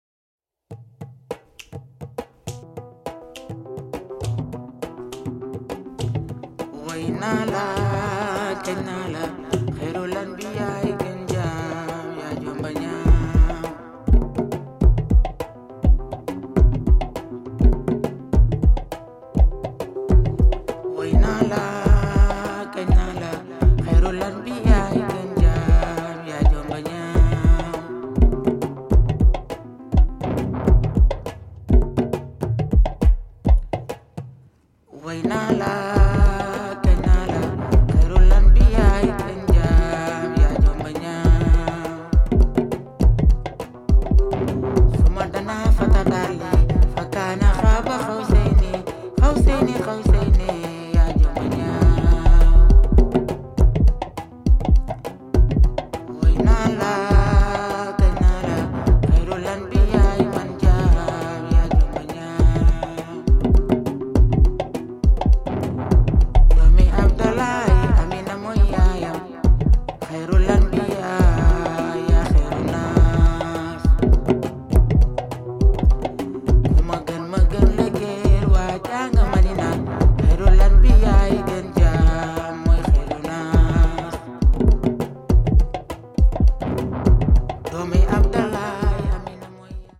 Dub techno
The instrumentation is radically pared down.